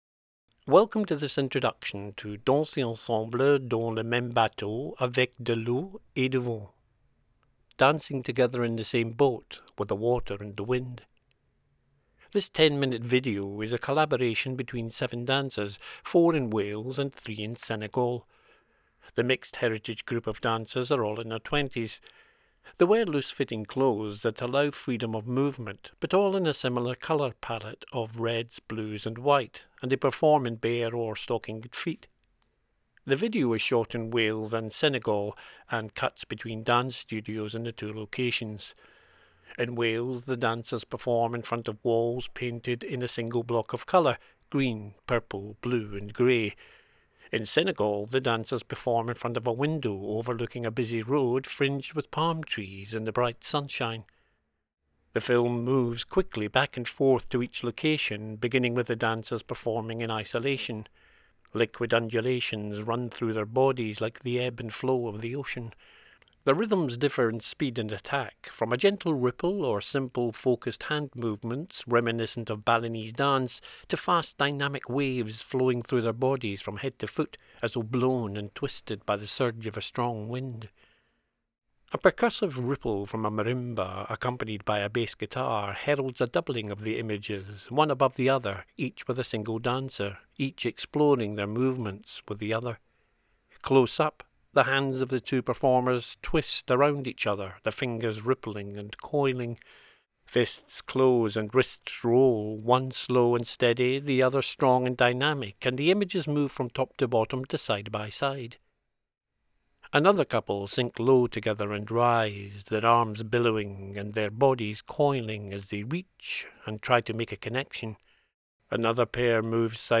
For an audio description of the film (produced by Sightlines Audio Description Services) use the following links: